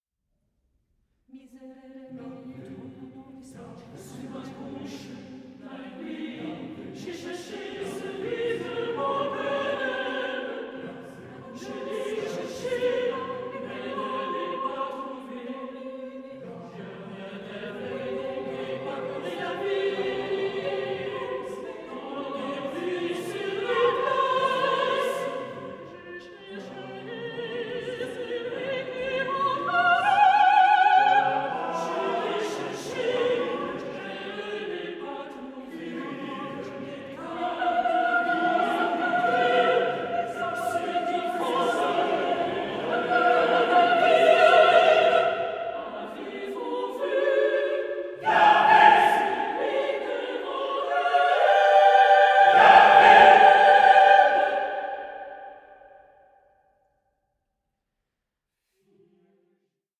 SACRED CHORAL MASTERWORKS